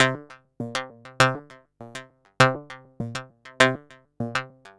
tx_synth_100_twangs_CDAbG1.wav